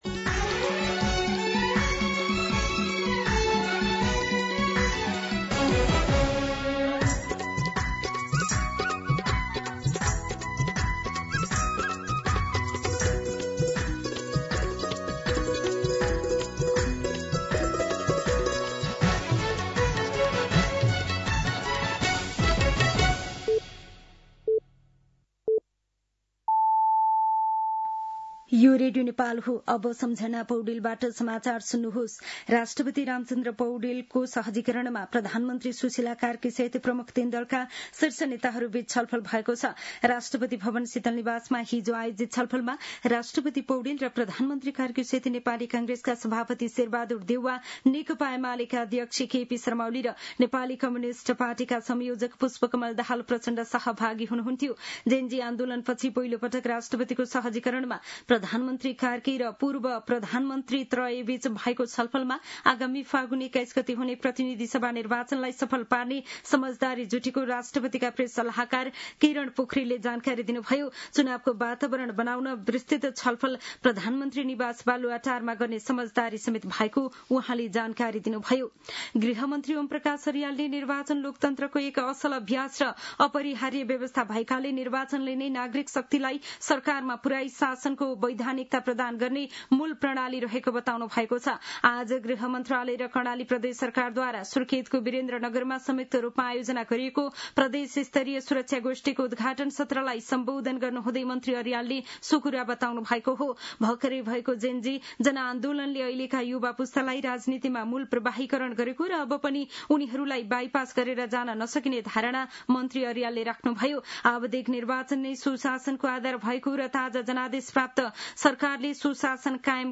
मध्यान्ह १२ बजेको नेपाली समाचार : ९ पुष , २०८२
12-pm-Nepali-News-1.mp3